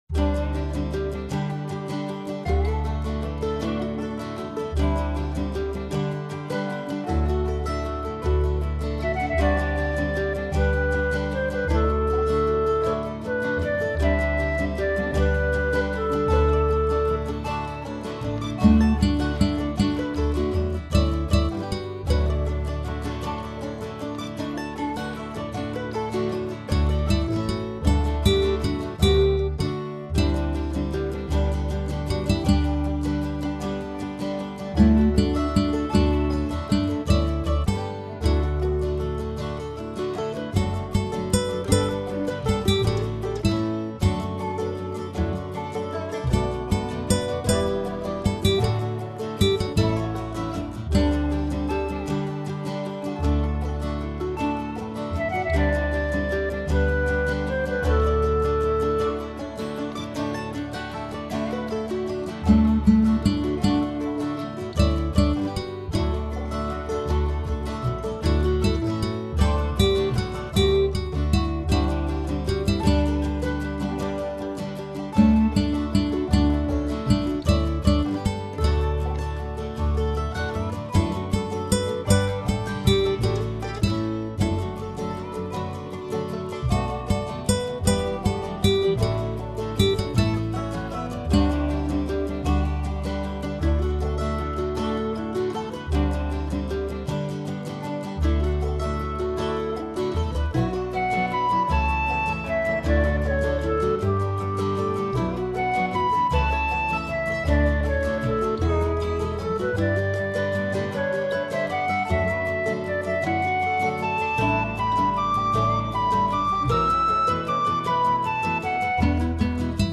lively setting
celtic reimagining